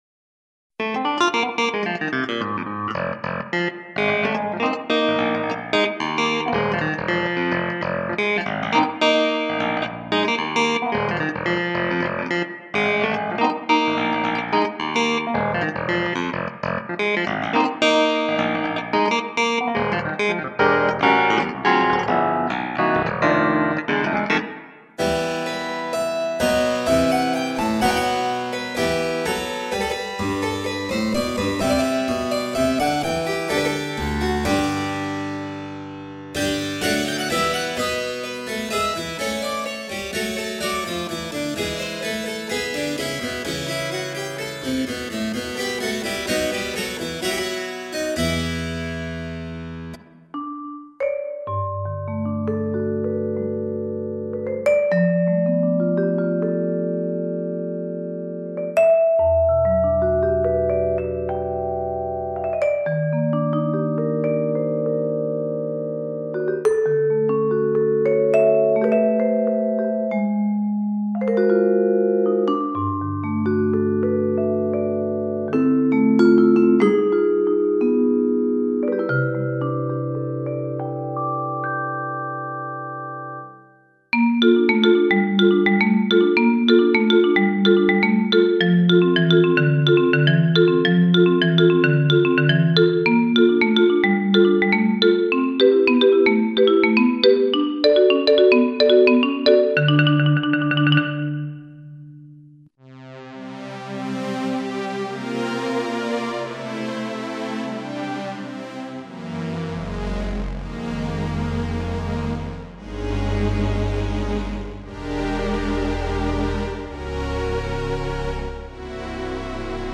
キーボード